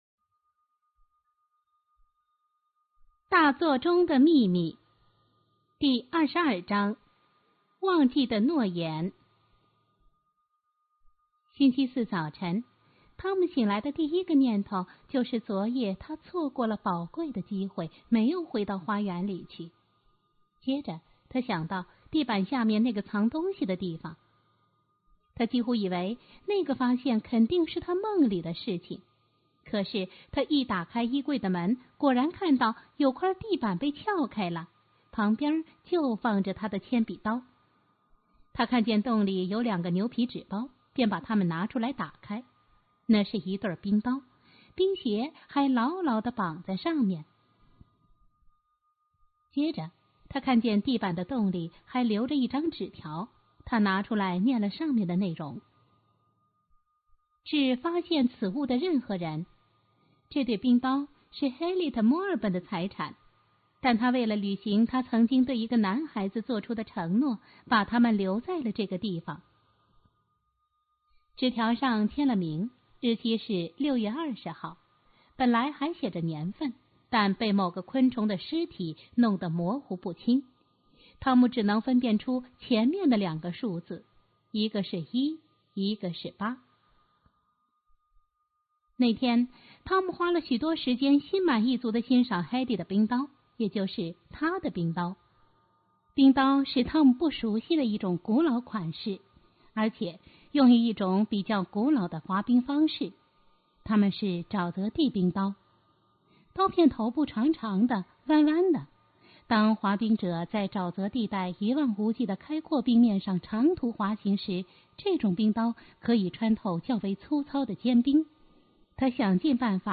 经典故事,听故事,mp3音频故事会